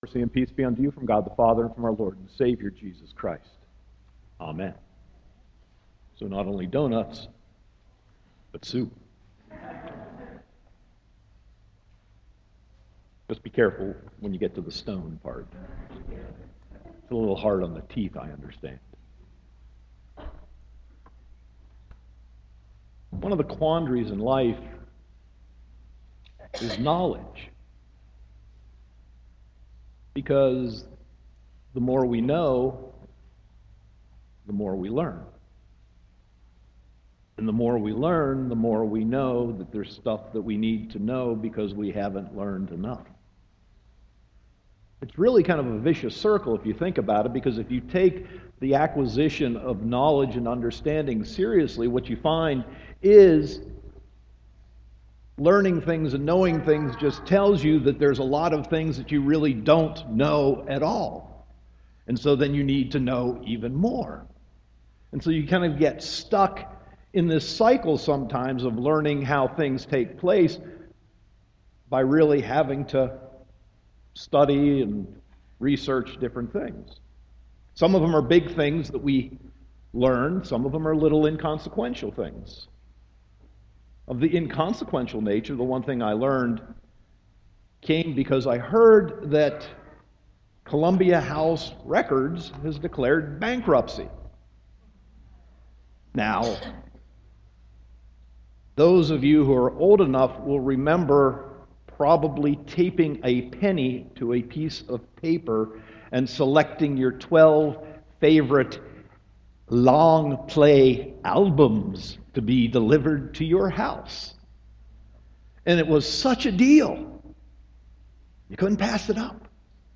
Sermon 8.16.2015